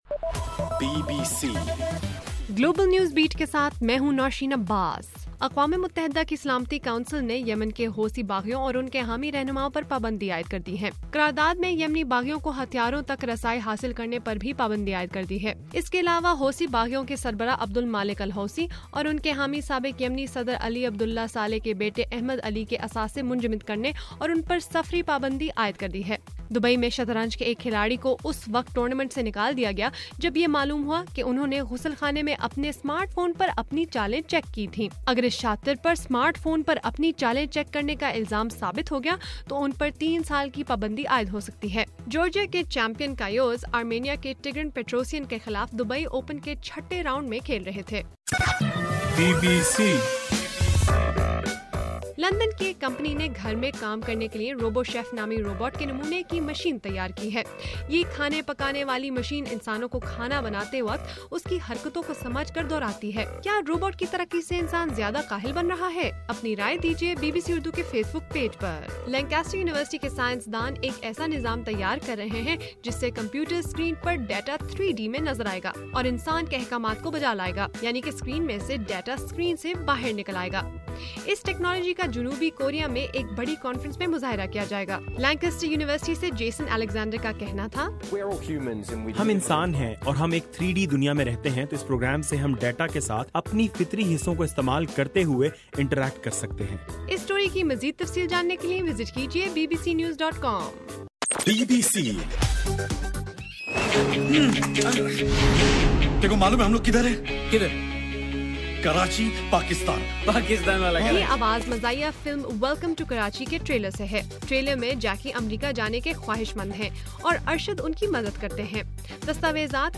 اپریل 14: رات 10 بجے کا گلوبل نیوز بیٹ بُلیٹن